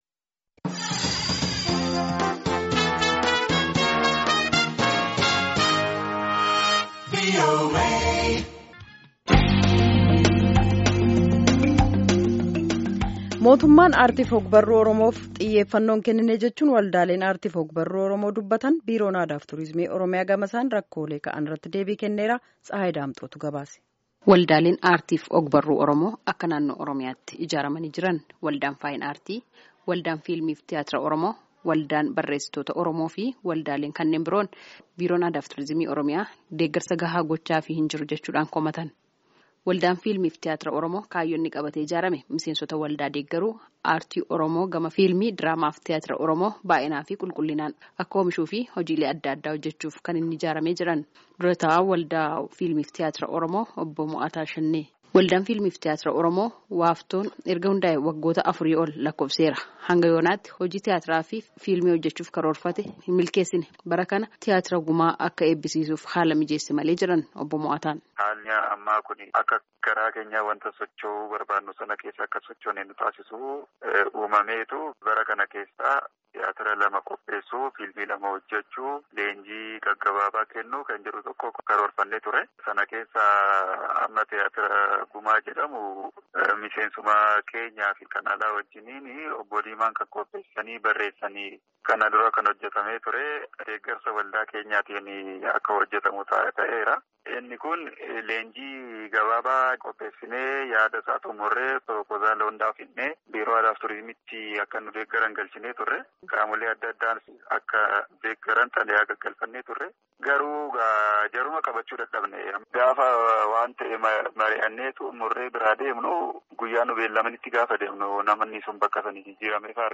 Gabaasaa guutuu caqasaa